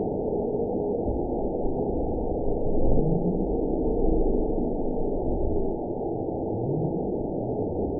event 919708 date 01/18/24 time 04:04:07 GMT (1 year, 3 months ago) score 8.93 location TSS-AB09 detected by nrw target species NRW annotations +NRW Spectrogram: Frequency (kHz) vs. Time (s) audio not available .wav